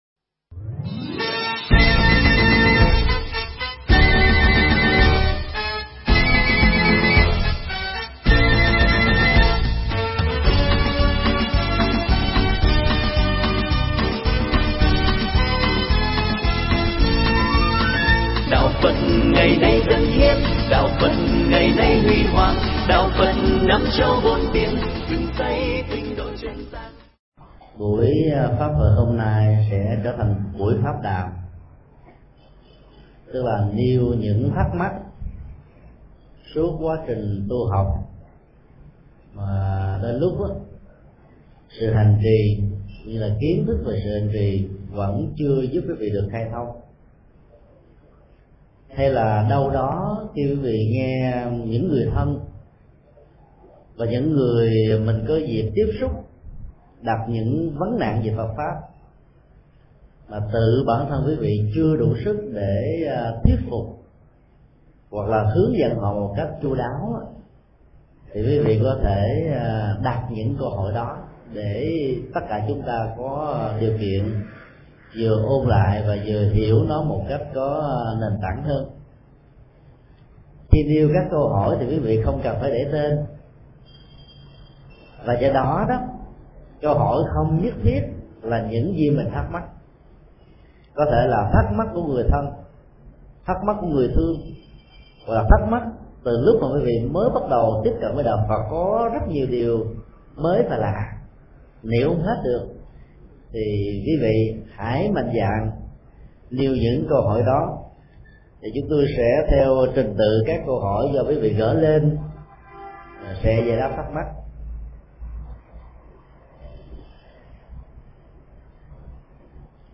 Mp3 Pháp Thoại Tu tập và vãng sinh – Thượng Tọa Thích Nhật Từ Giảng tại Chùa Đức Quang, ngày 18 tháng 11 năm 2006